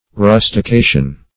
Rustication \Rus`ti*ca"tion\, n. [L. rusticatio.]